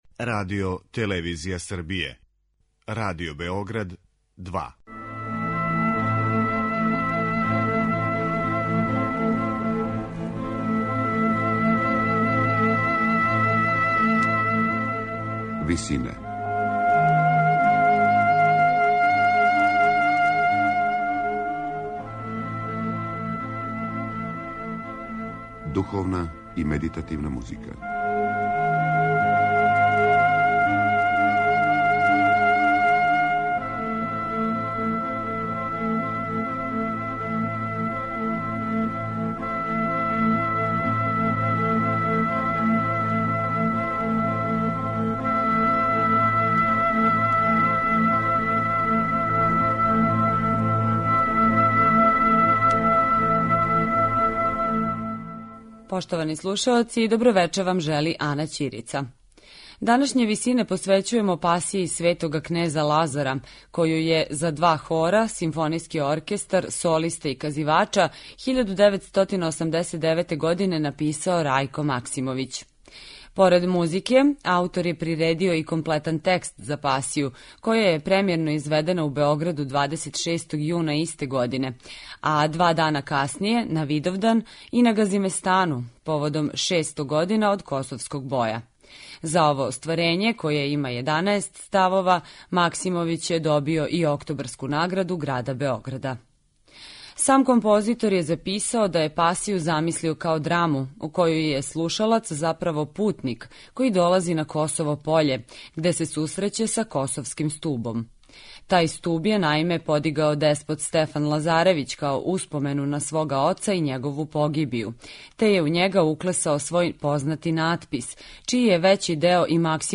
за два хора, симфонијски оркестар, солисте и казивача